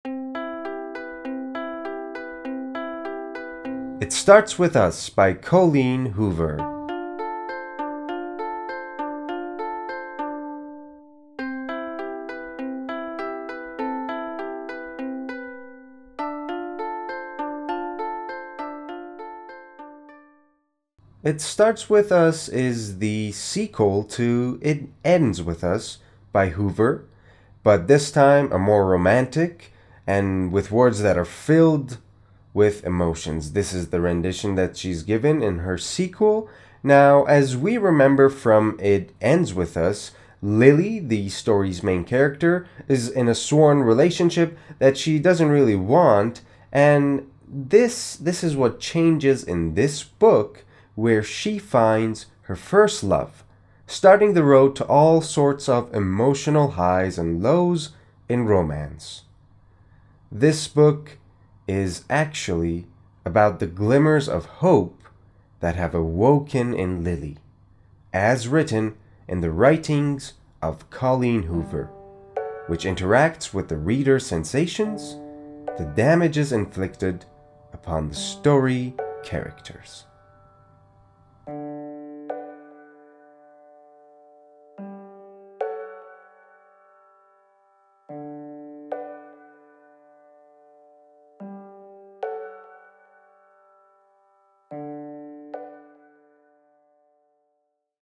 معرفی صوتی کتاب It Starts With Us